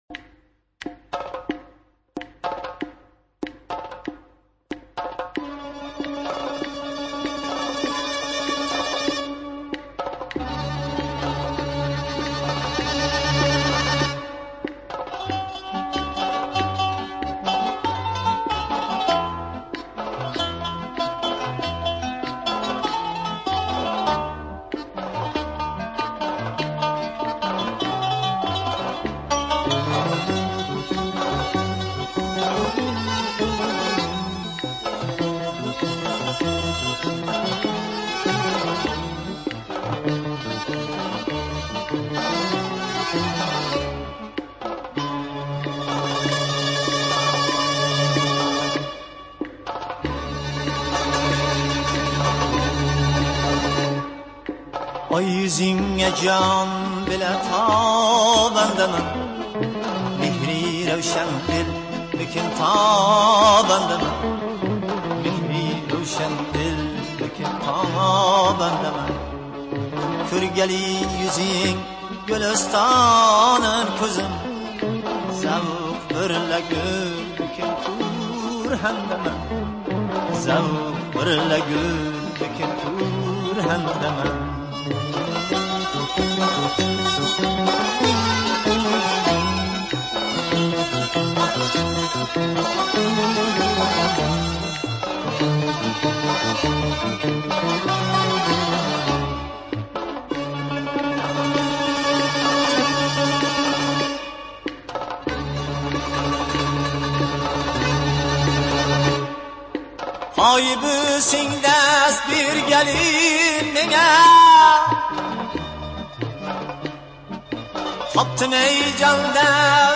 Мусиқа ва тарона Ўзбекистон мусиқаси